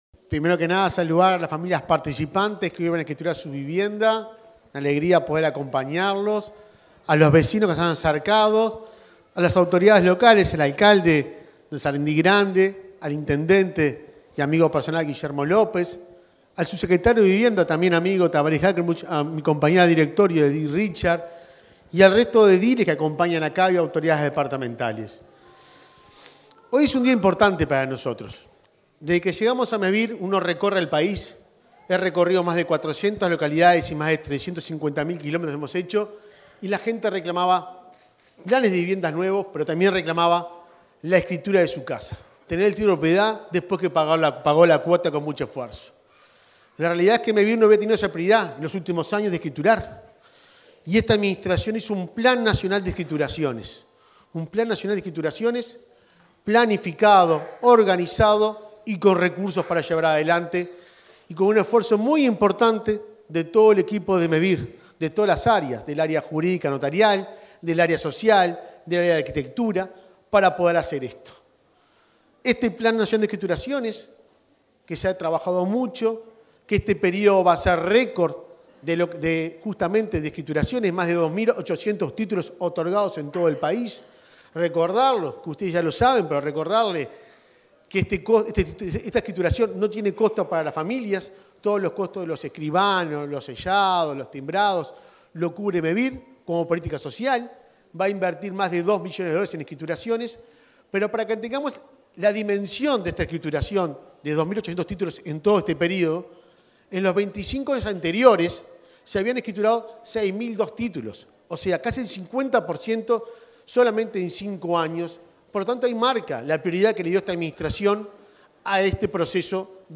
Palabras del presidente de Mevir, Juan Pablo Delgado
En el marco del acto de escritura de 120 viviendas en Sarandí Grande, este 10 de diciembre, se expresó el presidente de Mevir, Juan Pablo Delgado.